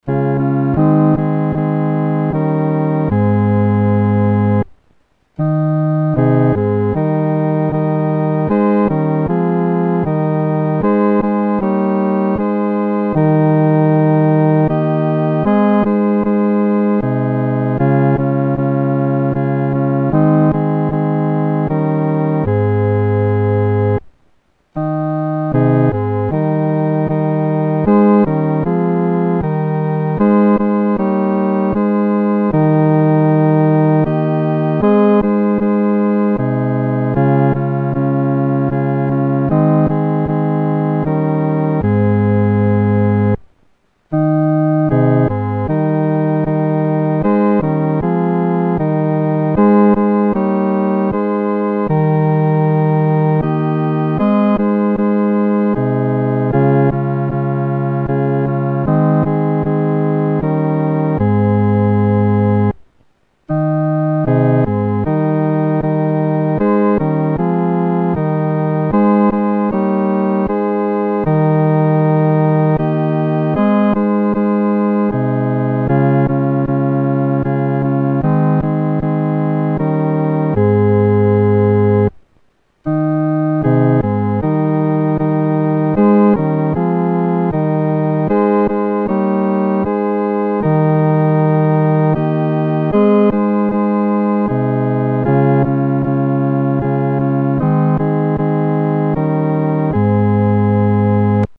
独奏（第四声）
万口欢唱-独奏（第四声）.mp3